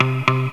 RIFFGTR 07-R.wav